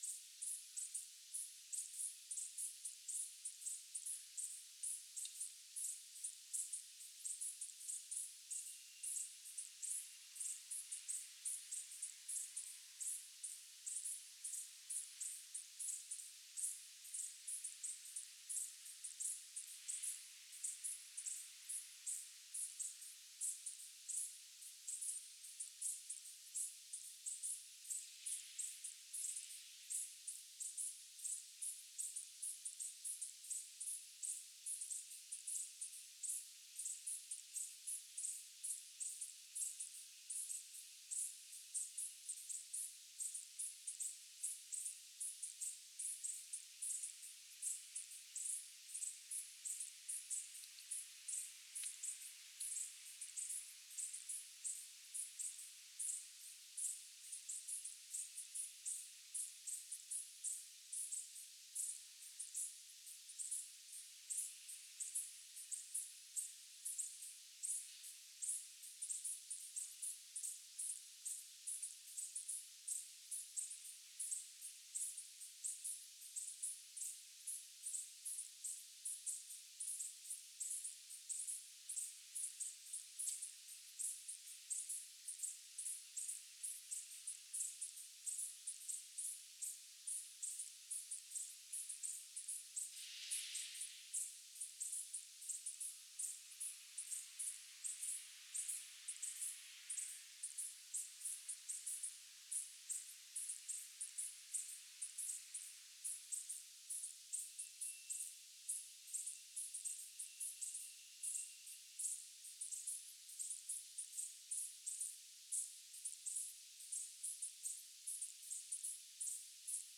insects